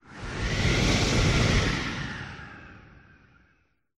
Звук искусственного порыва ветра
Библиотека Звуков - Звуки и звуковые эффекты - Студийные звуки